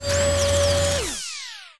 powerdown.ogg